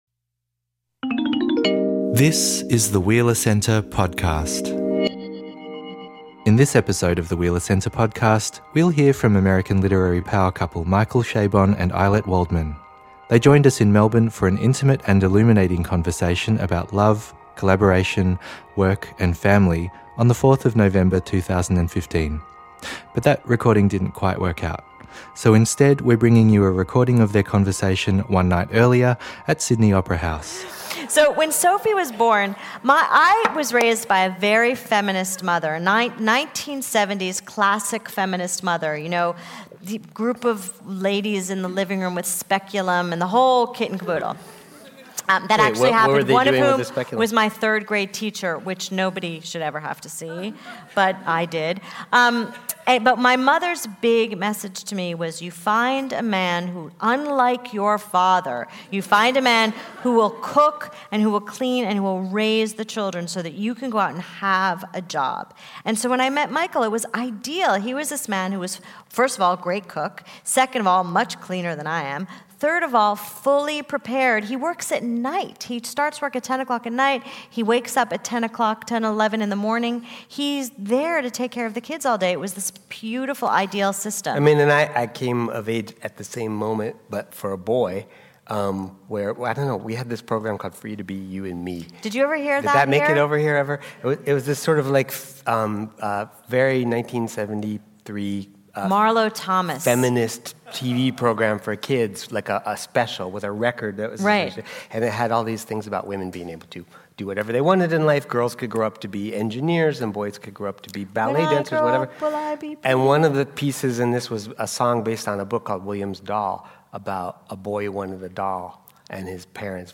Join this power couple of American literature as they interview each other on family and creativity. Find out how their partnership extends across various aspects of their lives, from raising children, to making marriage work and balancing their literary endeavours.